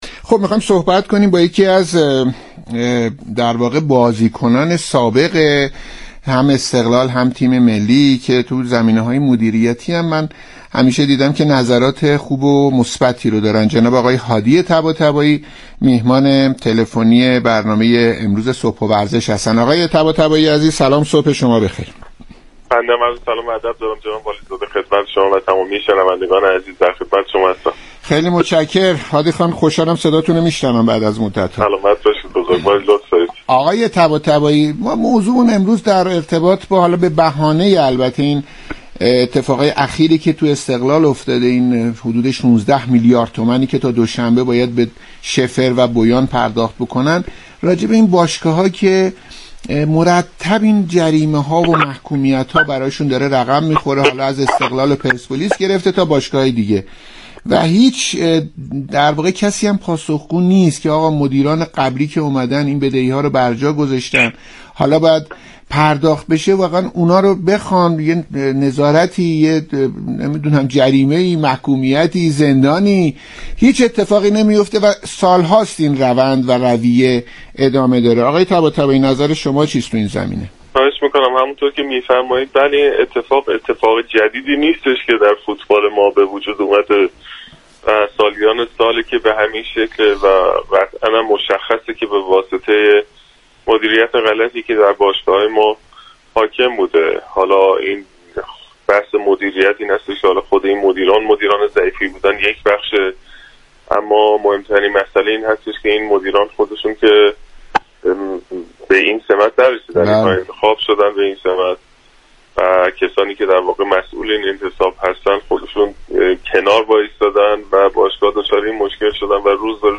برنامه «صبح و ورزش» شنبه 24 خرداد در گفتگو با